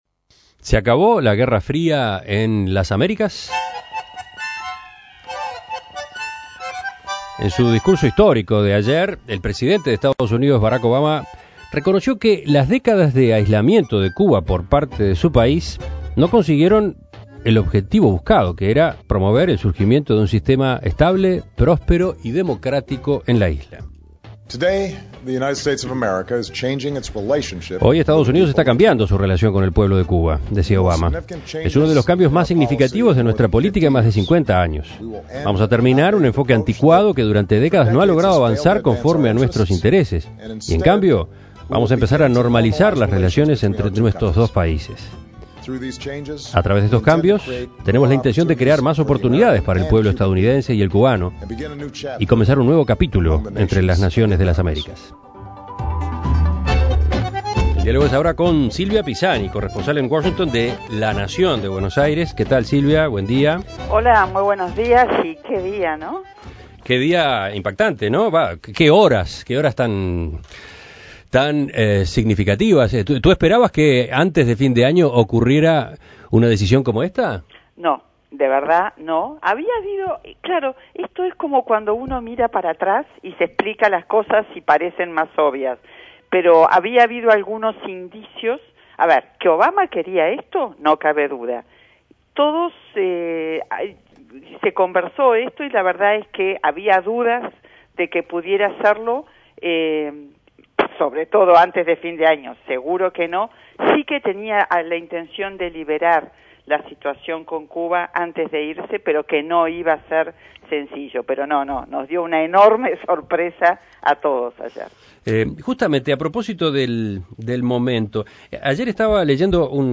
(Audio Barack Obama.)